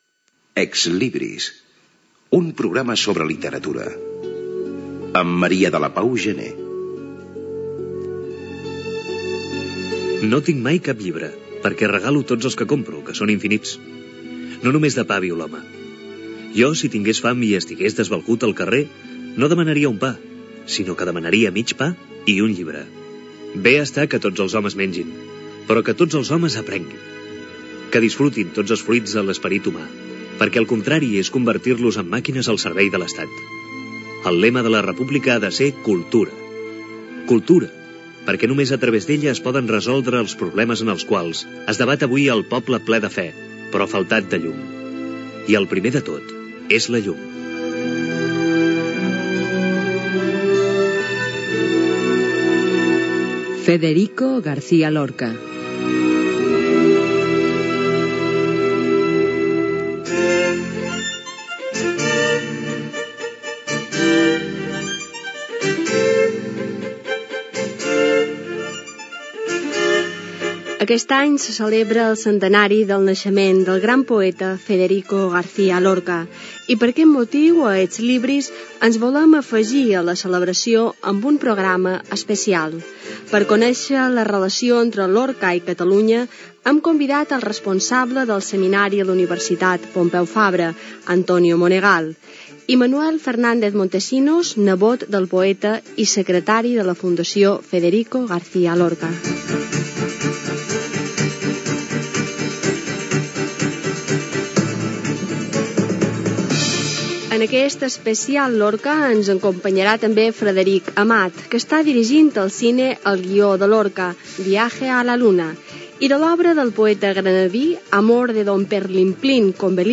lectura d'un text d'Antonio Machado (veu Antonio Banderas)
Cultura
FM